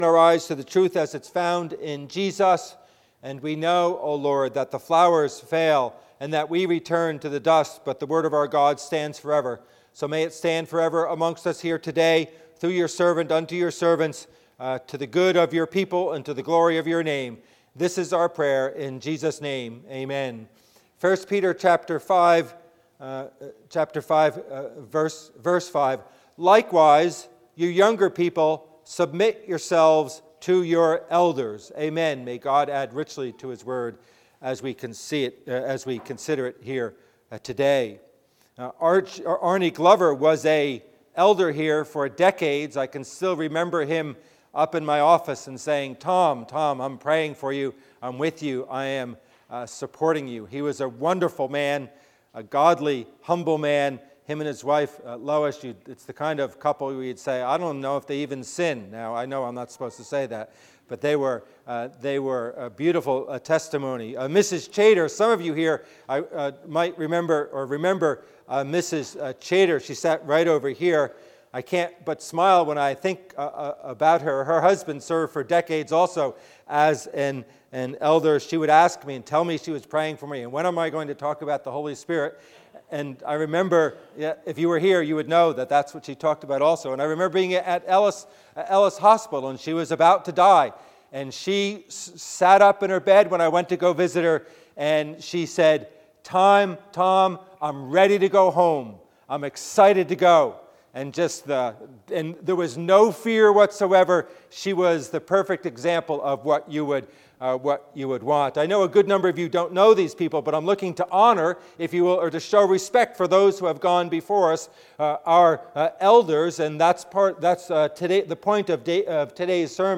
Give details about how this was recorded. Passage: 1 Peter 5:5 Service Type: Worship Service